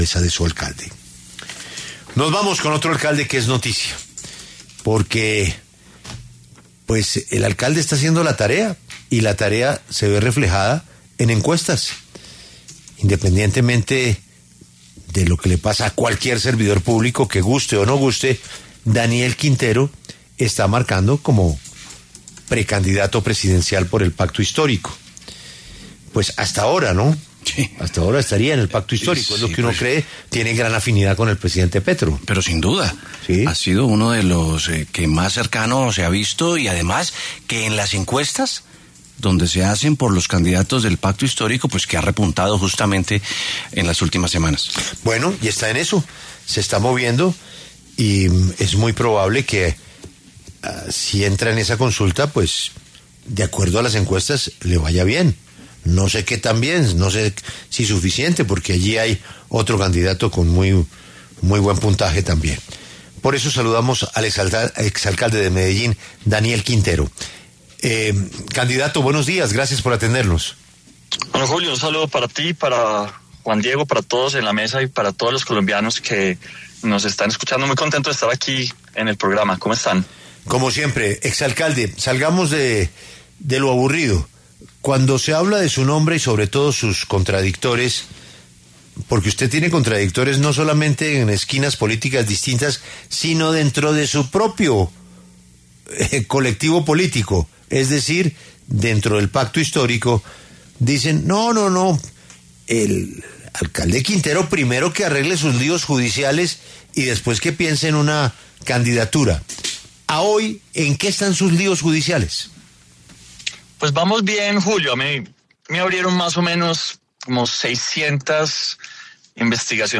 Daniel Quintero, exalcalde de Medellín y precandidato presidencial, habló en La W sobre el panorama electoral de cara a las elecciones de 2026.